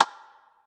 spinwheel_tick_05.ogg